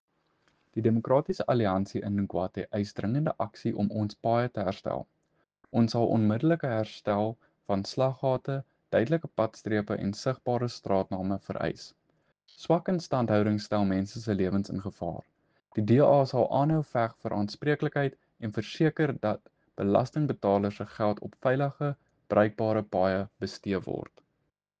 Afrikaans soundbites by Cllr JP de Villiers and